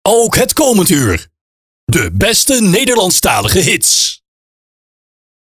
Zonder Soundeffects
03 - Ook het komend uur - De beste Nederlandstalige hits (Voice Only).mp3